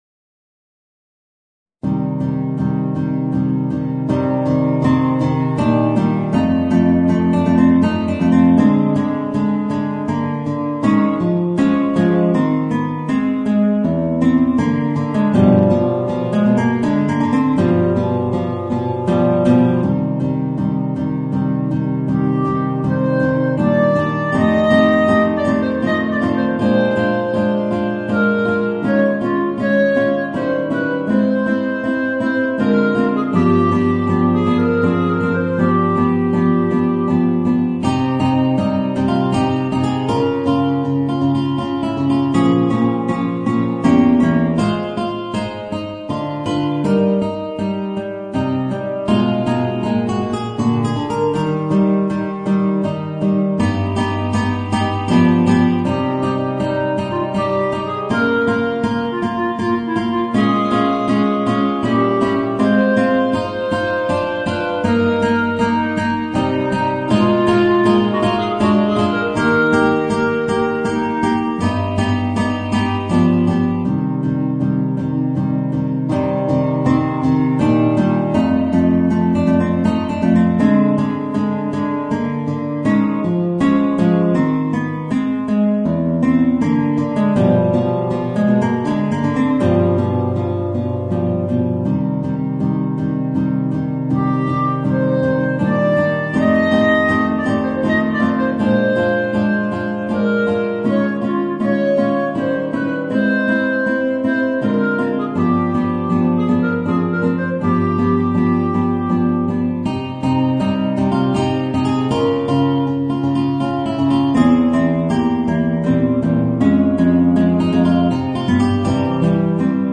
Voicing: Guitar and Clarinet